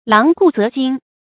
狼顾麕惊 láng gù qiān jīng 成语解释 比喻惊恐万状。